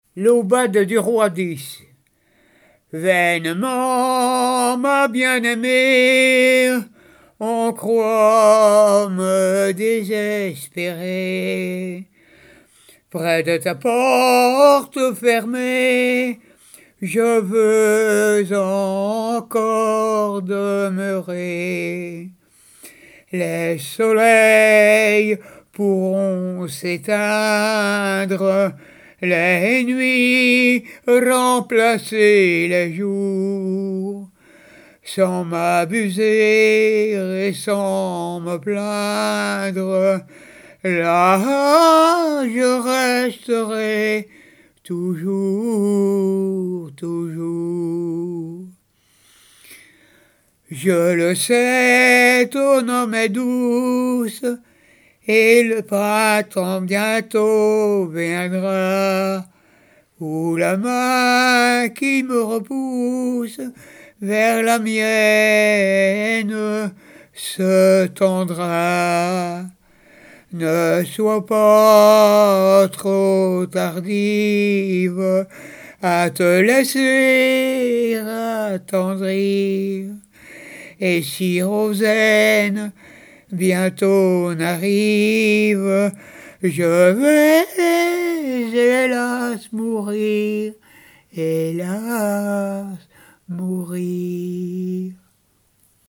Jard-sur-Mer
Genre strophique
Pièce musicale inédite